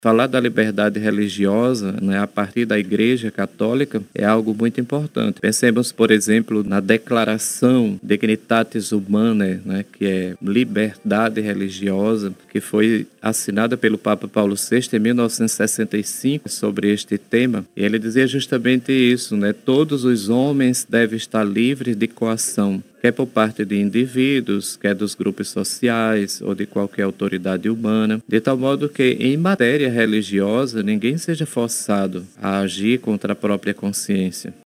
Em entrevista à Rádio Rio Mar